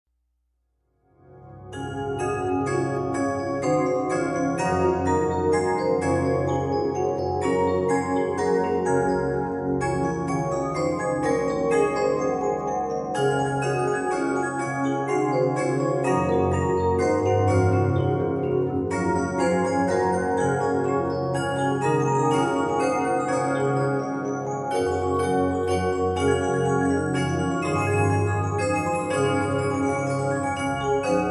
Music Box In the Garden